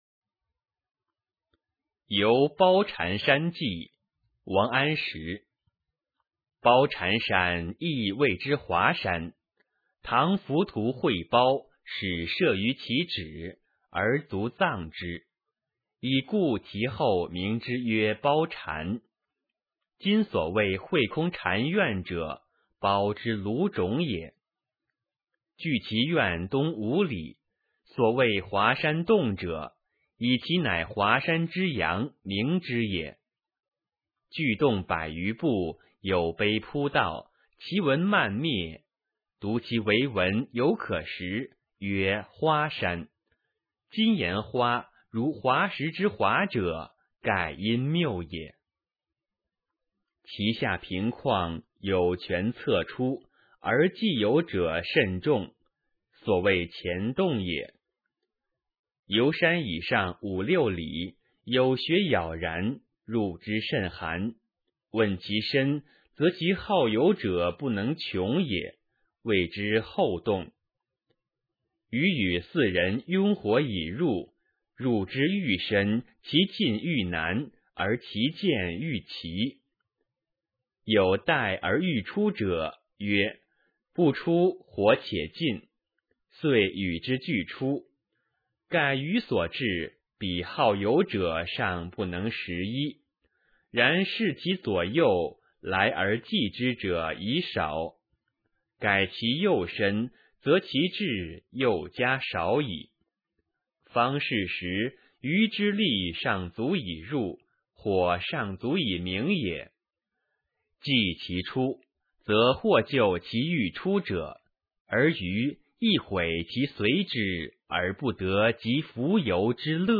《游褒禅山记》原文和译文（含赏析、朗读）